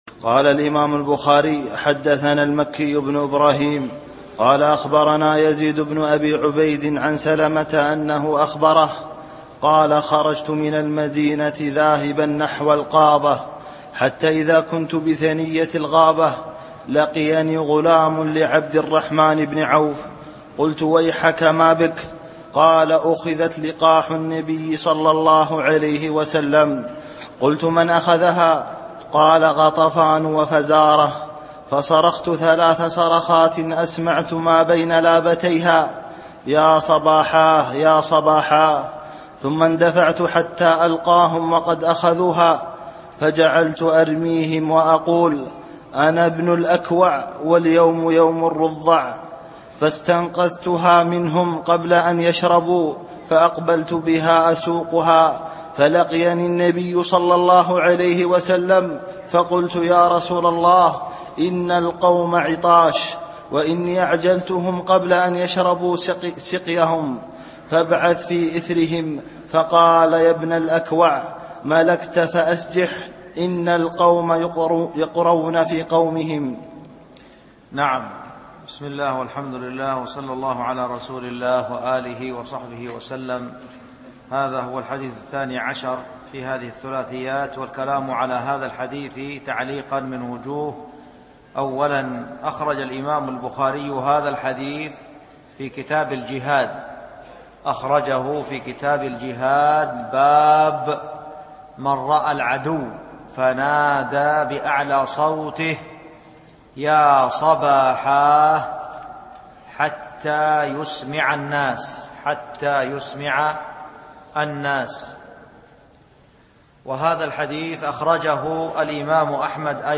الألبوم: شبكة بينونة للعلوم الشرعية المدة: 30:08 دقائق (6.94 م.بايت) التنسيق: MP3 Mono 22kHz 32Kbps (VBR)